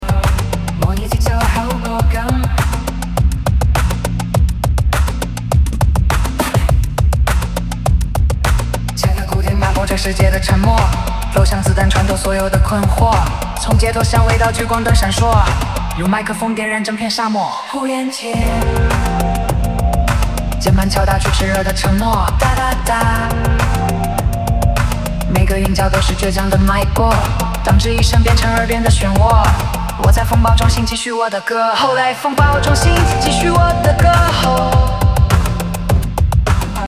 （模拟节奏口播感）
Verse1起拍带点轻踩地板的声儿——
Chorus突然加重力
人工智能生成式歌曲